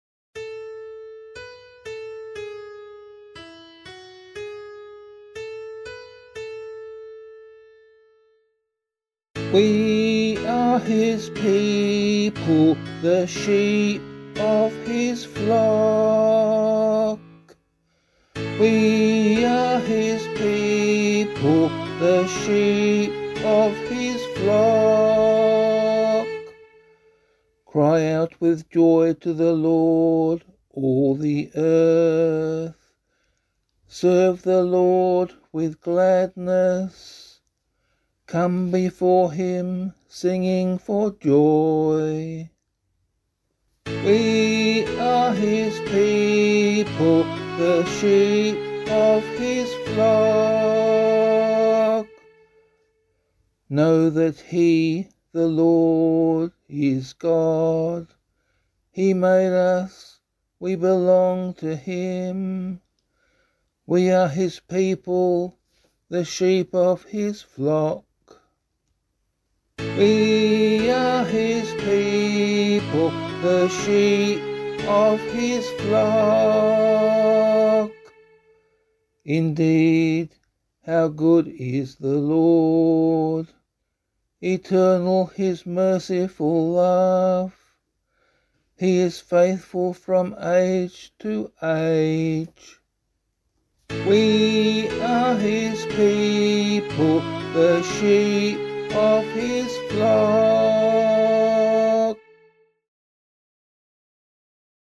026 Easter 4 Psalm C [APC - LiturgyShare + Meinrad 8] - vocal.mp3